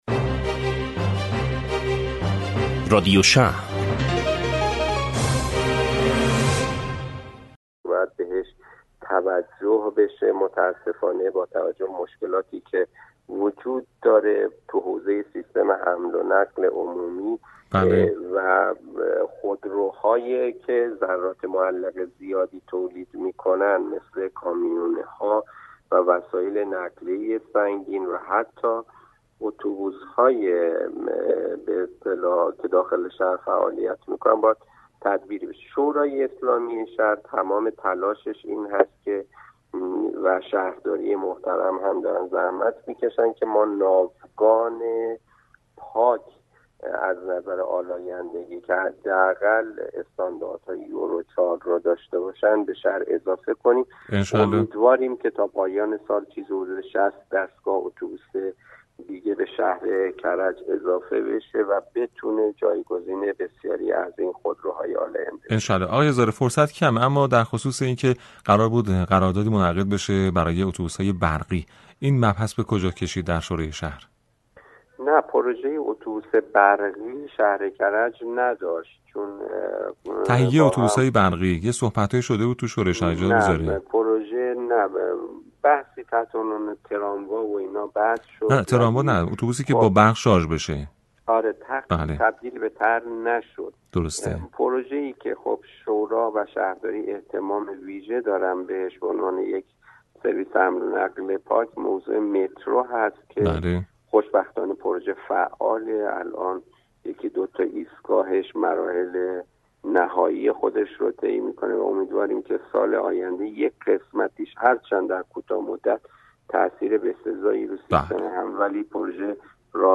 گفت و گوی رادیو شهر با عضو شورای شهر کرج
عباس زارع، عضو شورای اسلامی شهر کرج با رادیو اینترنتی مدیریت شهری شهرداری کرج گفت و گو کرد.